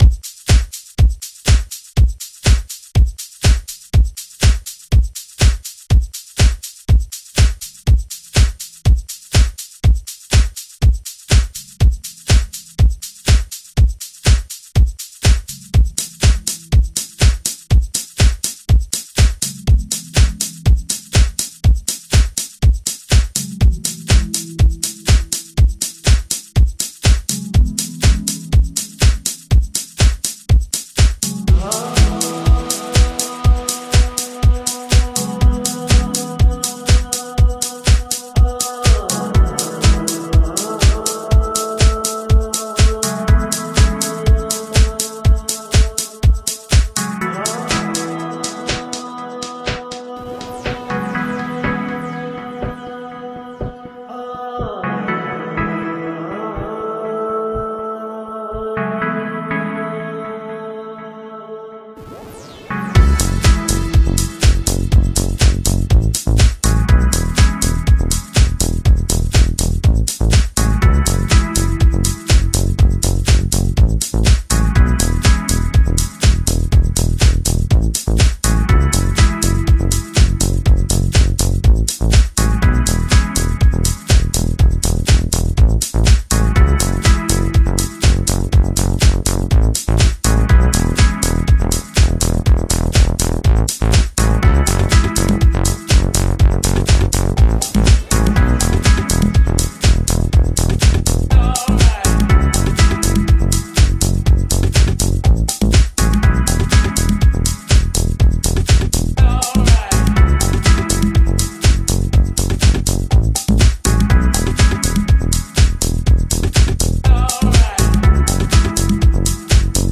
Relatively new producer on the House scene